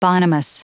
Prononciation du mot bonhomous en anglais (fichier audio)
Prononciation du mot : bonhomous